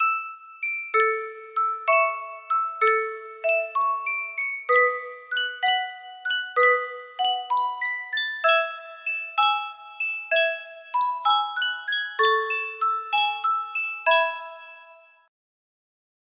Sankyo Music Box - In My Merry Oldsmobile AKE music box melody
Full range 60